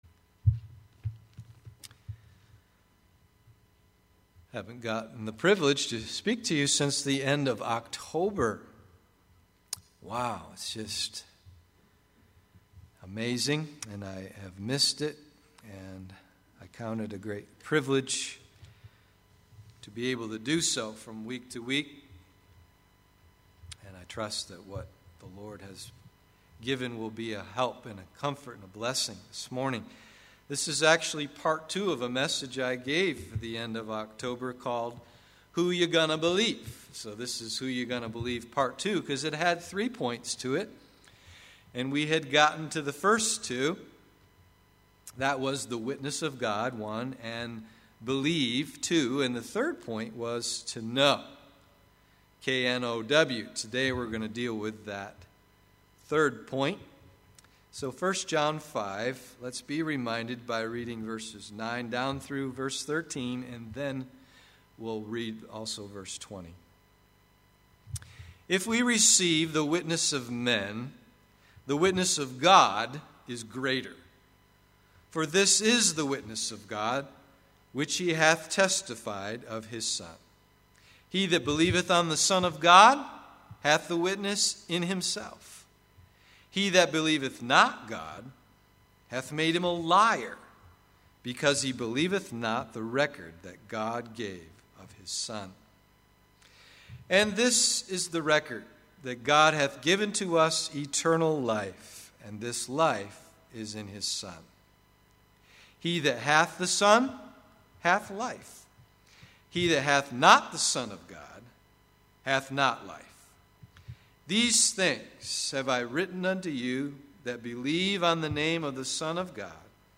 “Who You Gonna Believe?” (part 2) Sunday AM